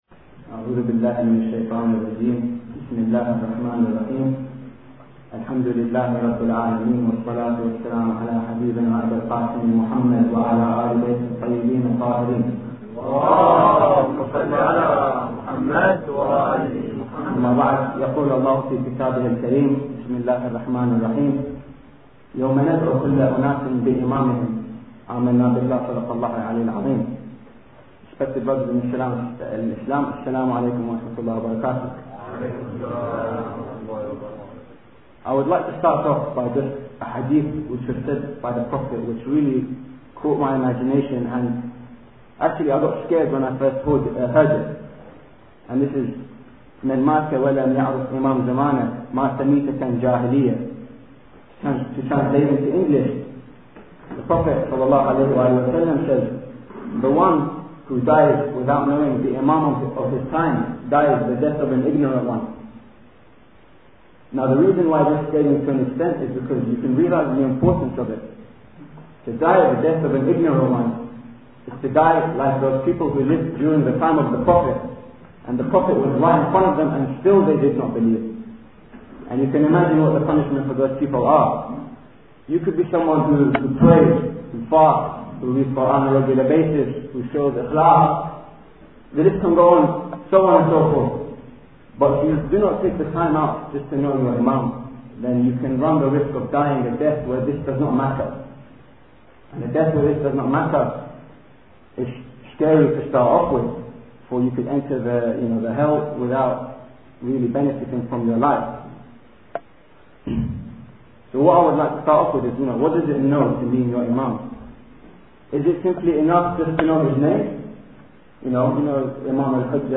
Audio Library\lectures\1